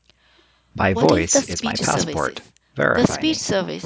I have downloaded files from Github repository, mixed two files into one wave file using Audacity.